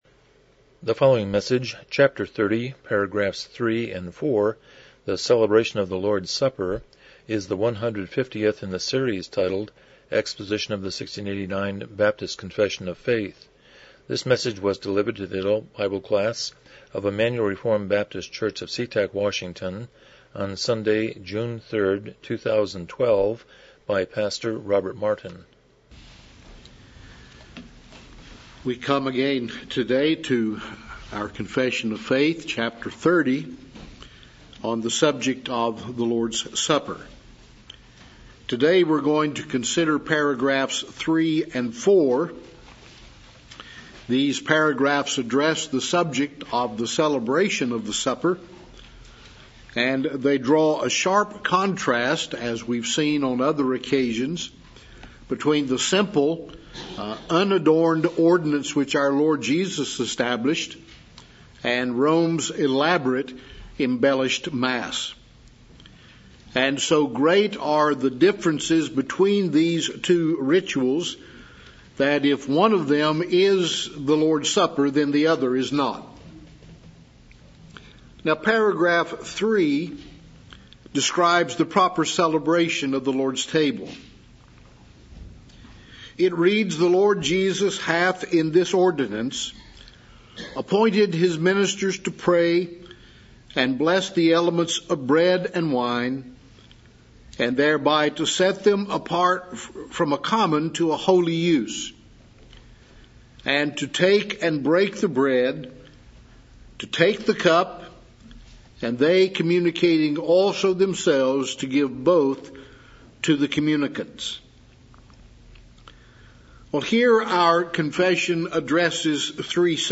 1689 Confession of Faith Service Type: Sunday School « 3 The Preface